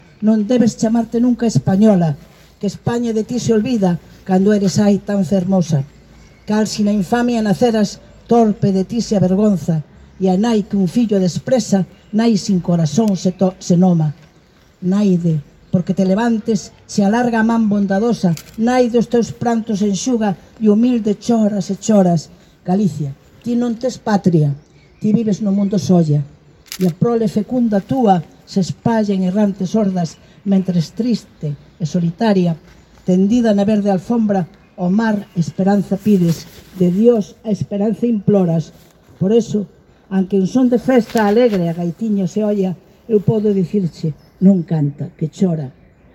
El acto contó con una lectura pública de poemas de Rosalía y con la lectura del manifiesto de la AELG por el Día de Rosalía, y participaron diversos estudiantes de centros educativos de la ciudad.